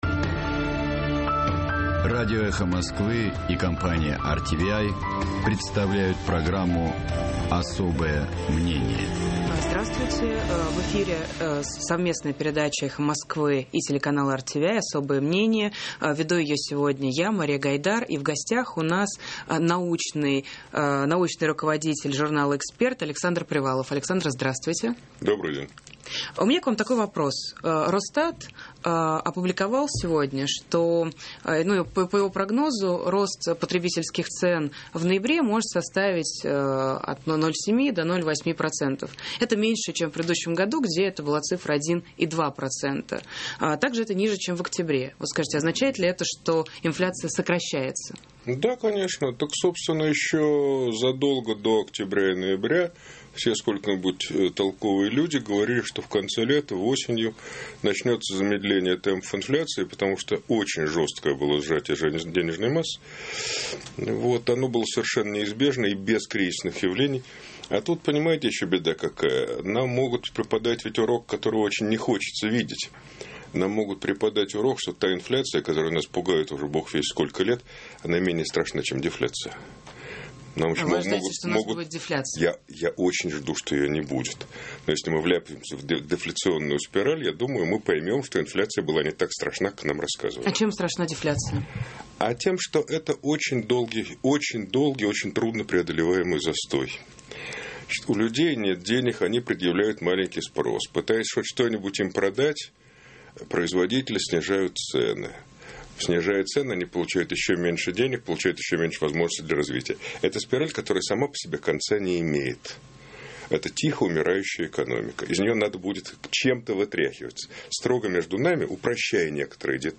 В эфире совместная передача «Эхо Москвы» и телеканала RTVi «Особое мнение».